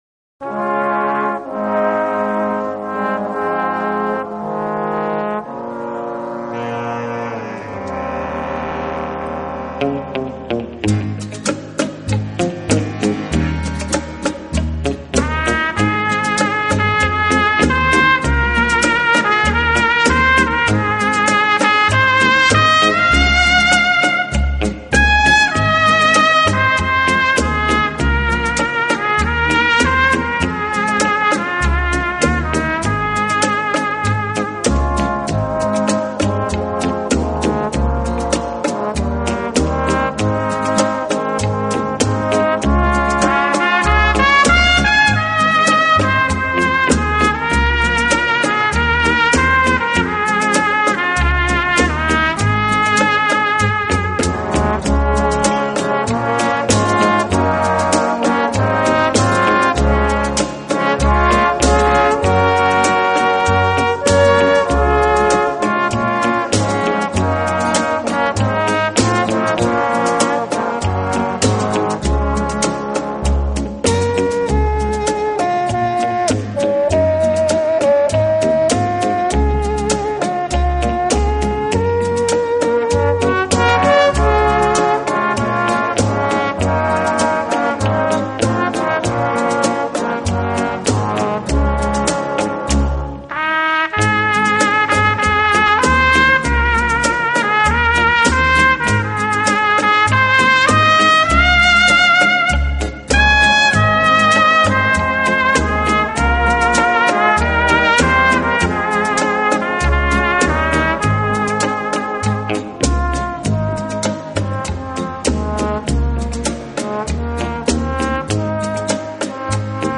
小号的音色，让他演奏主旋律，而由弦乐器予以衬托铺垫，音乐风格迷人柔情，声情
并茂，富于浪漫气息。温情、柔软、浪漫是他的特色，也是他与德国众艺术家不同的